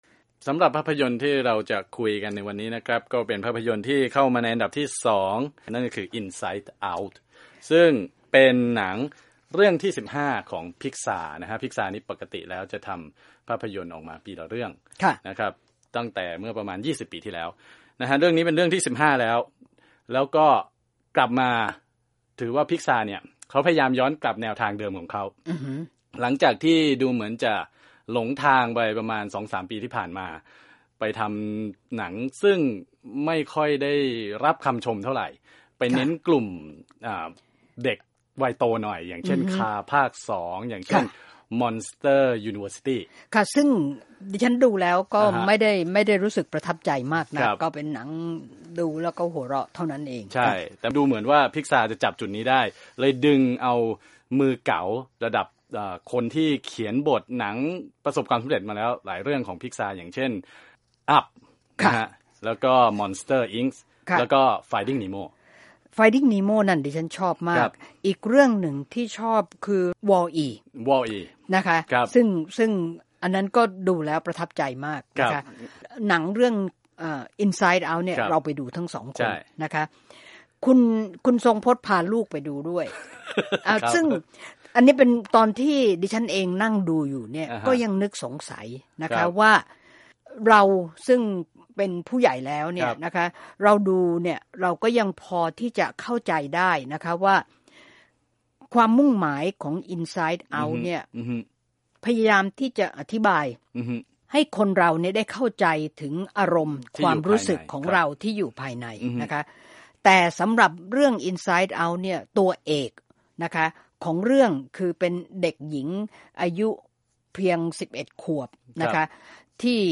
วิจารณ์ภาพยนตร์ Inside Out